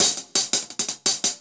Index of /musicradar/ultimate-hihat-samples/170bpm
UHH_AcoustiHatC_170-01.wav